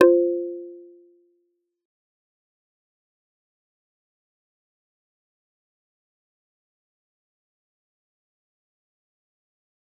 G_Kalimba-F4-f.wav